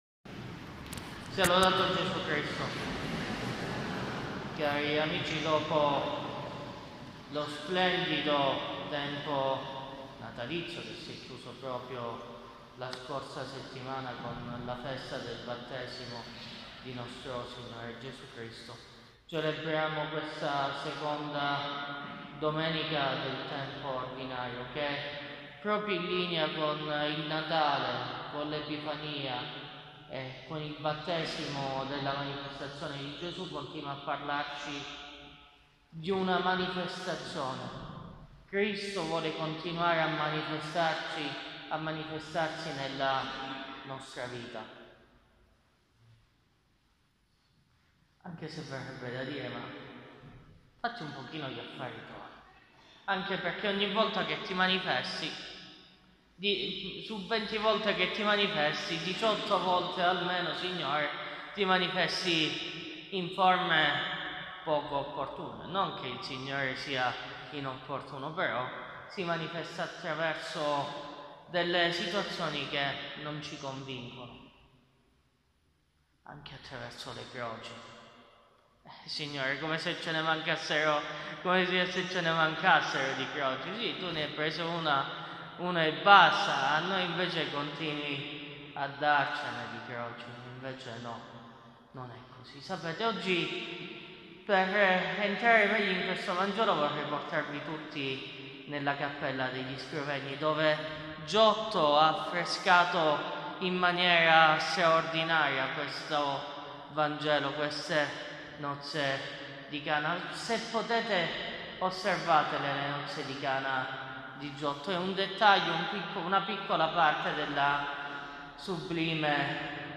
Omelia della II domenica del Tempo Ordinario